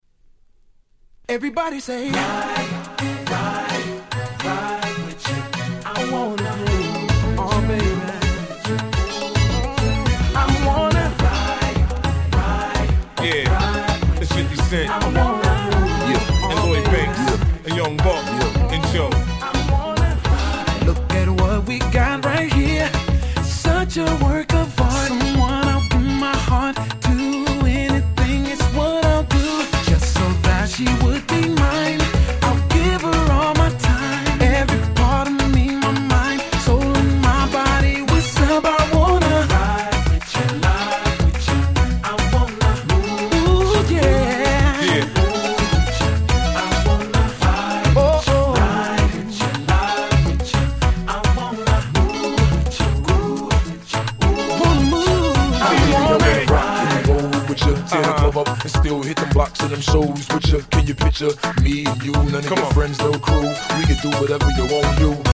White Press Only Nice Mush Up !!